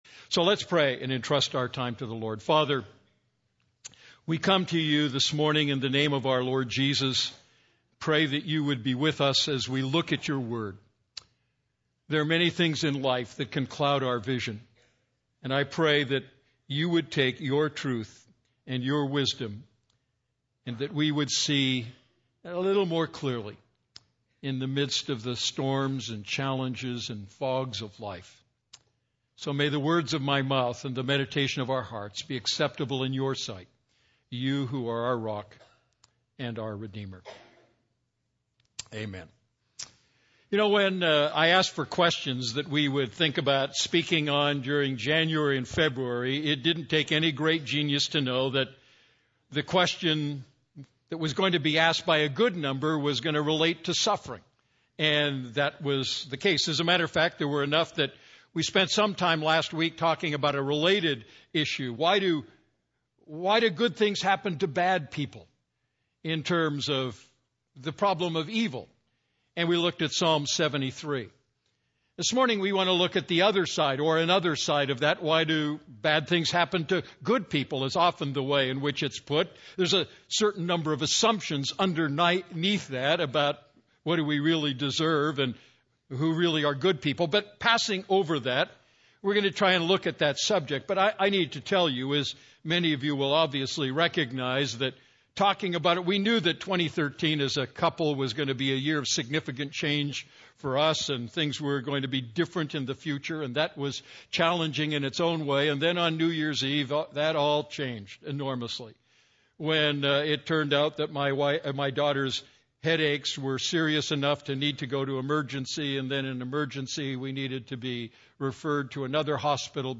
A message from the series "Great Questions?."